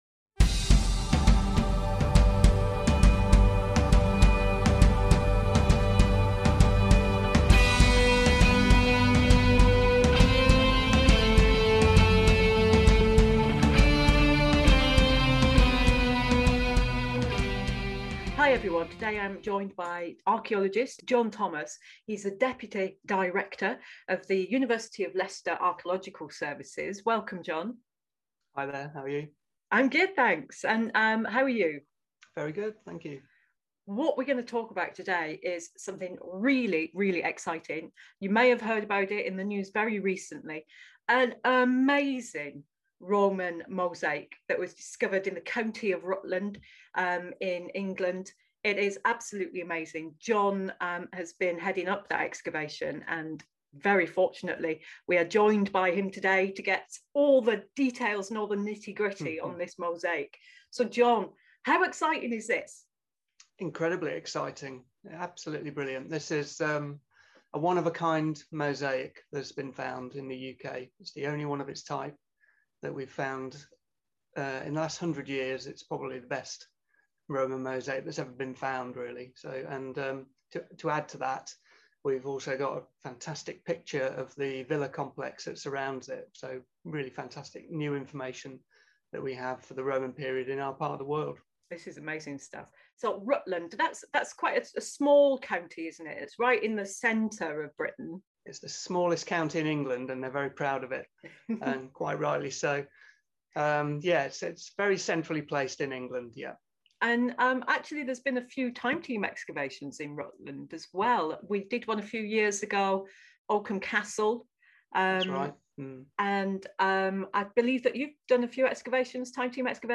The original video of this interview can be watched here.